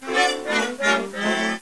MG_neg_buzzer.ogg